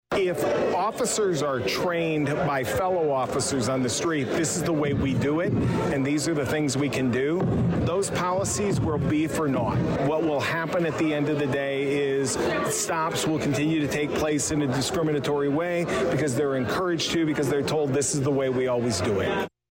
A passionate crowd attended Saturday’s (March 4th) speech